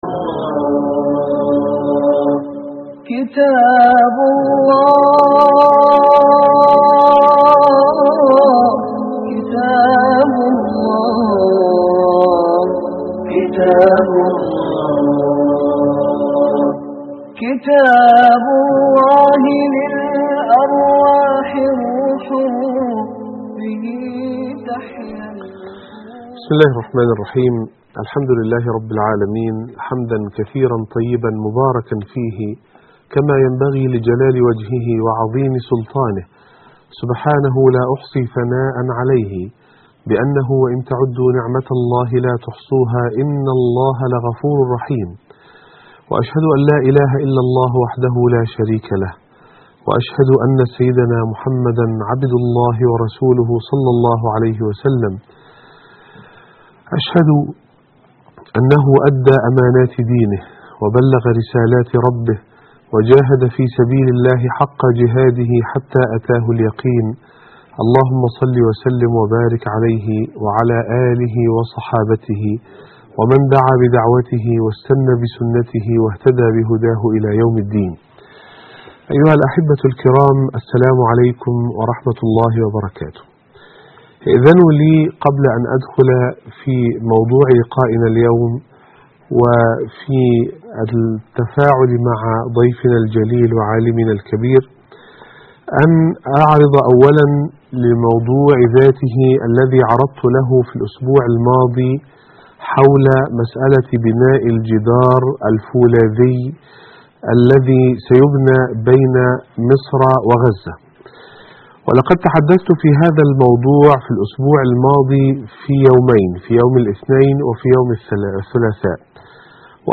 السنة الصحيحة والطعن فيها (2009-12-29) لقاء - الشيخ حازم صلاح أبو إسماعيل